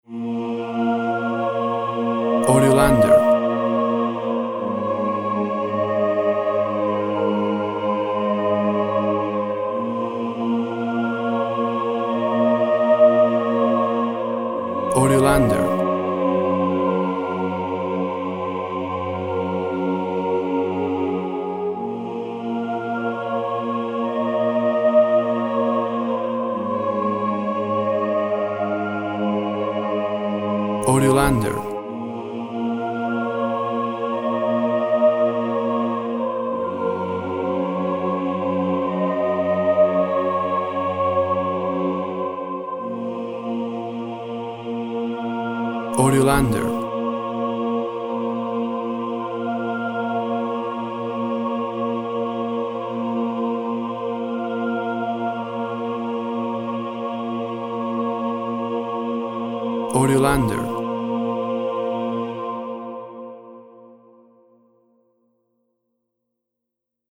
WAV Sample Rate 24-Bit Stereo, 44.1 kHz
Tempo (BPM) 48